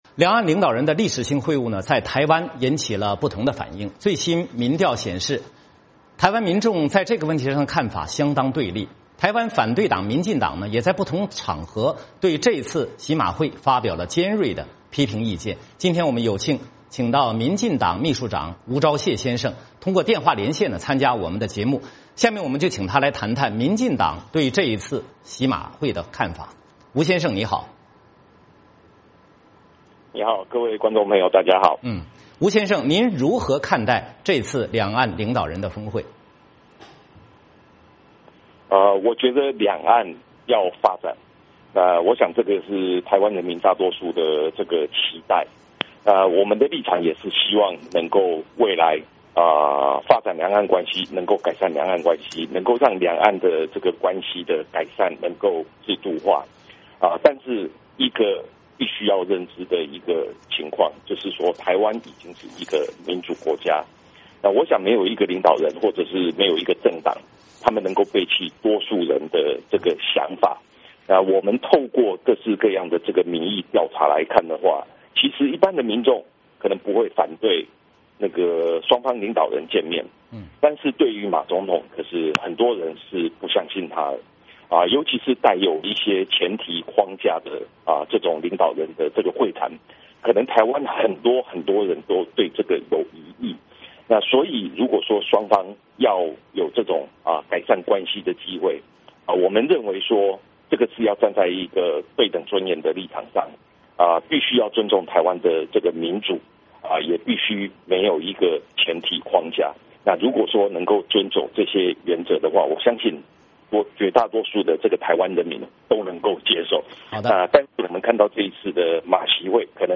台湾反对党民进党也在不同场合对这次马习会发表了尖锐的批评意见。今天我们请到民进党秘书长吴钊燮先生通过电话连线参加我们的节目，我们就请他来谈谈民进党对这次马习会的看法。